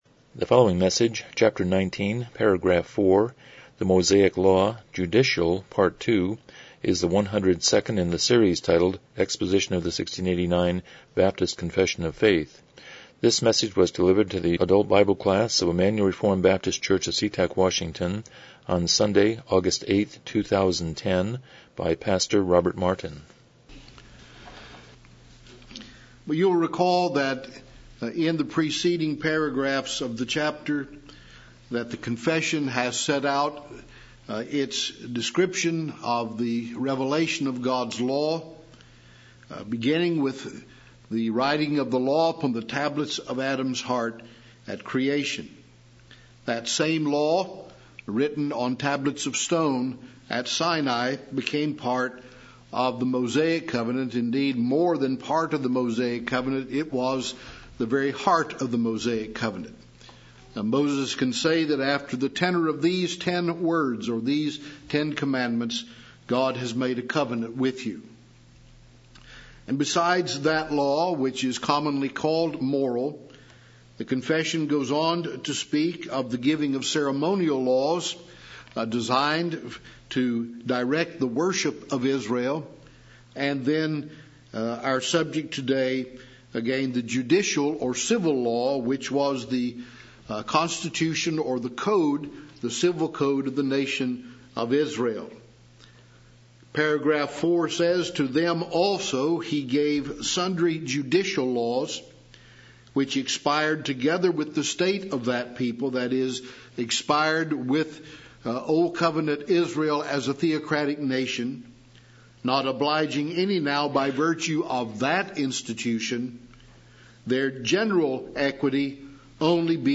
1689 Confession of Faith Service Type: Sunday School « The Miracle at Nain